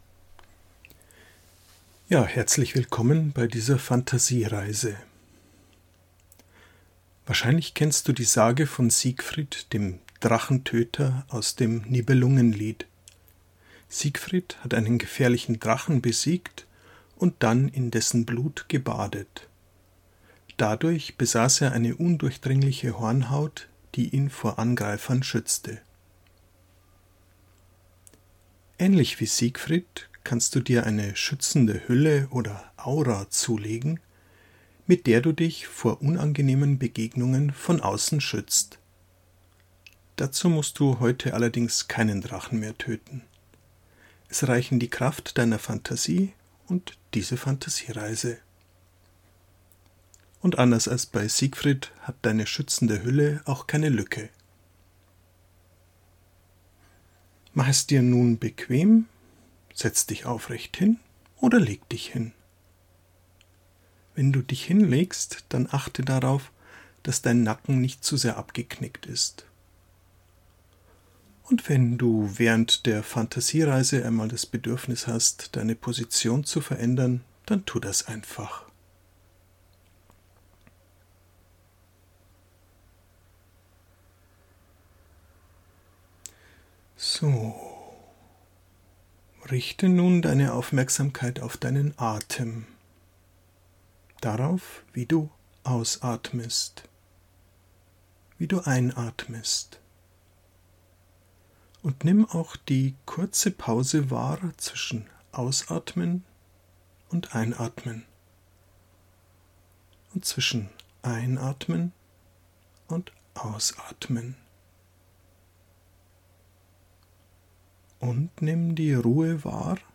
Die Fantasiereise
Fantasiereise-Schuetzende-Huelle.mp3